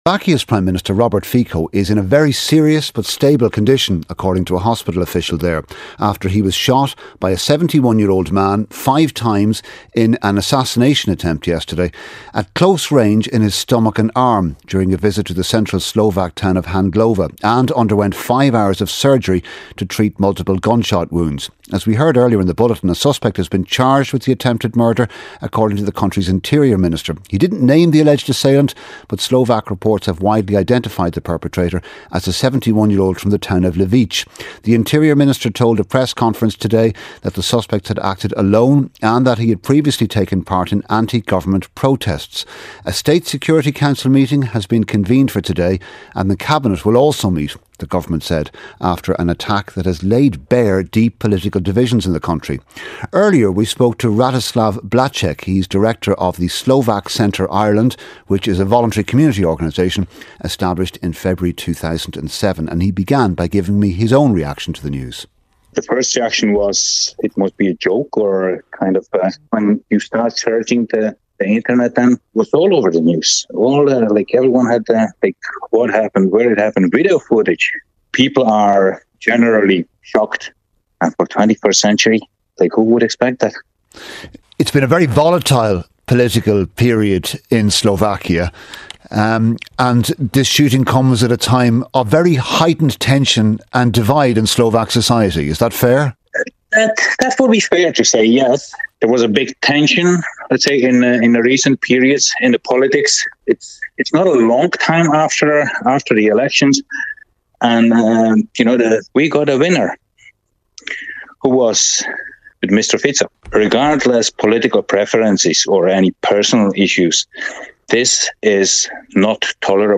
8:35am Sports News - 18.04.2024